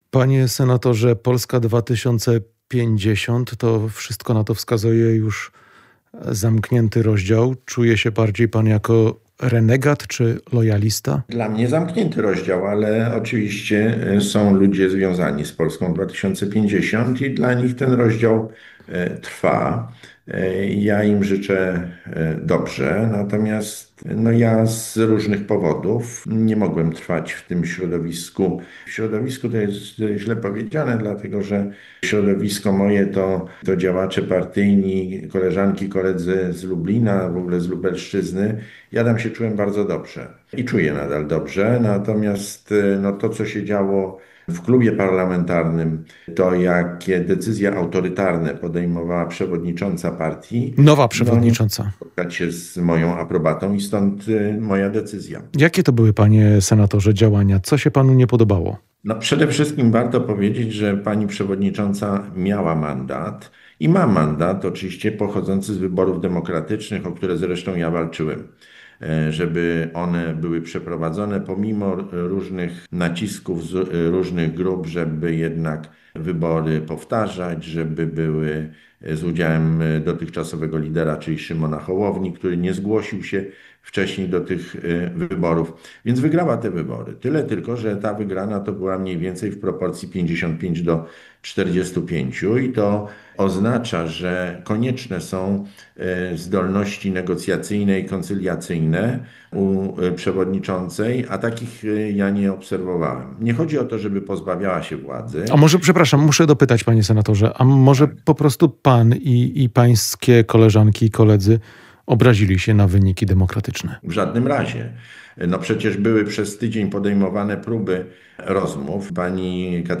Rozmowa z senatorem Jackiem Trelą